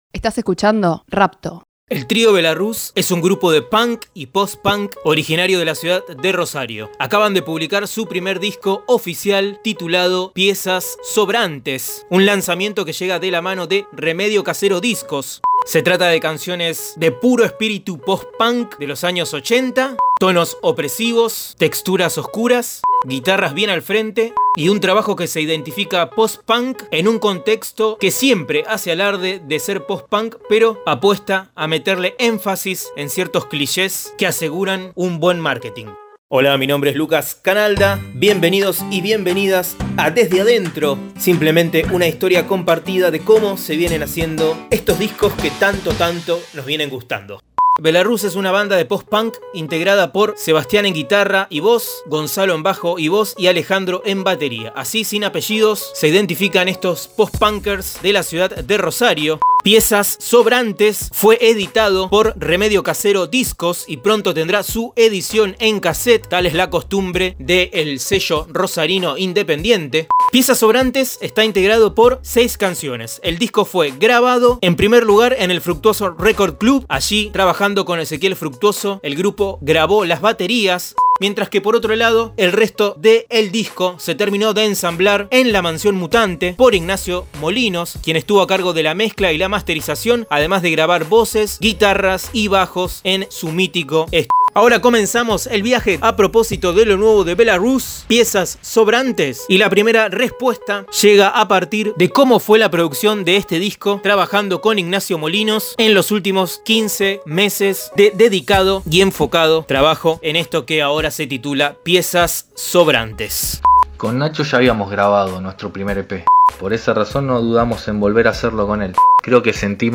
Los discos más recientes desde la voz de sus protagonistas.